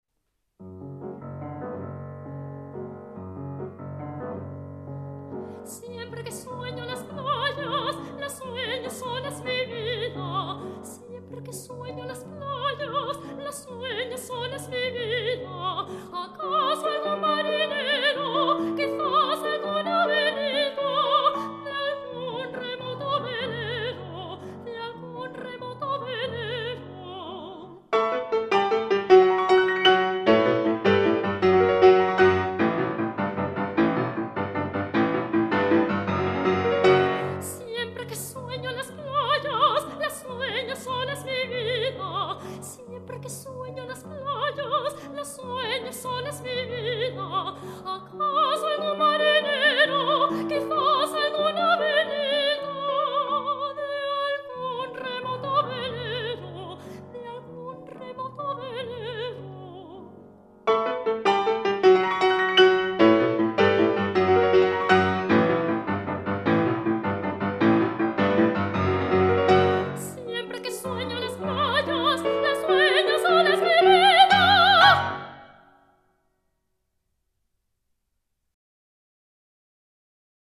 Lieder und Arien